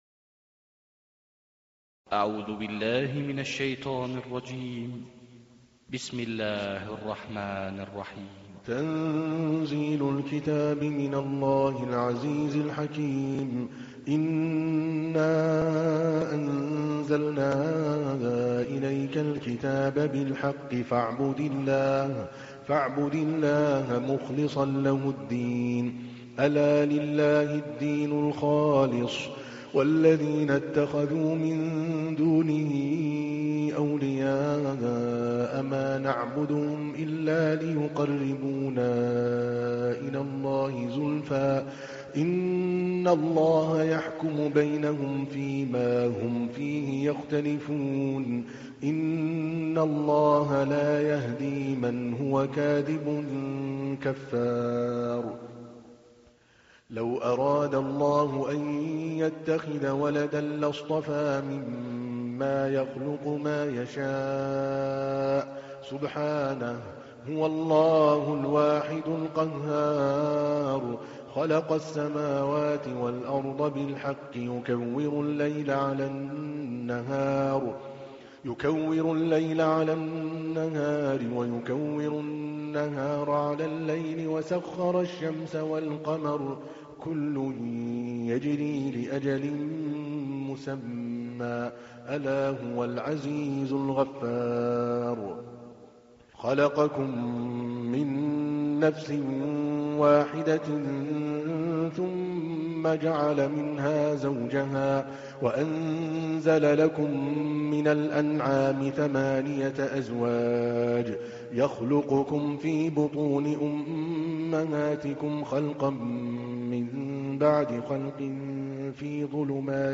تحميل : 39. سورة الزمر / القارئ عادل الكلباني / القرآن الكريم / موقع يا حسين